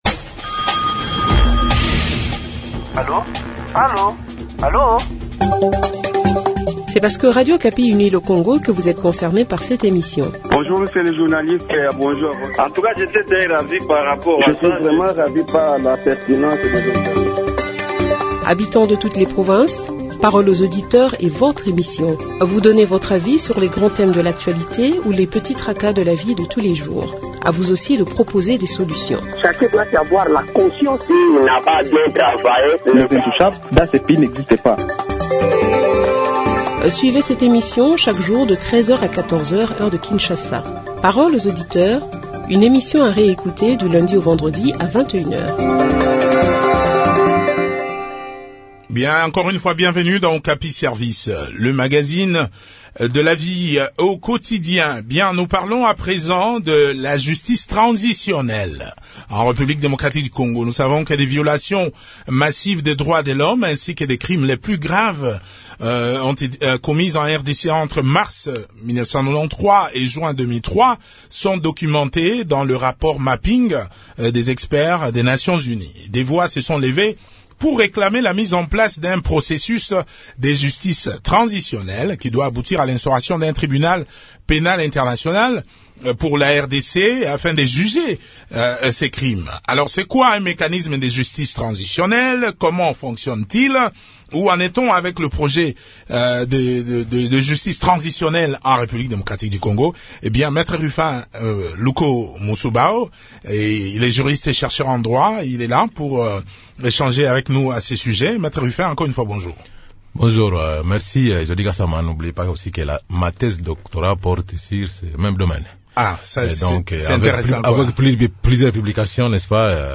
Eléments de réponse dans cet entretien